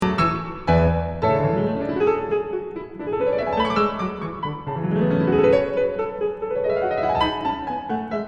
I have to apologize for the recording here, I haven’t gotten to the studio yet. So this is actually recorded at home, in my practicing studio. And as you can hear, I have practiced some, the piano is very out of tune.
Five more step downwards.